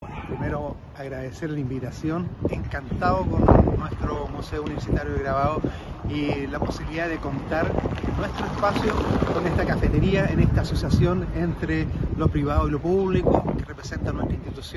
Inauguración de Cafetería Rapelli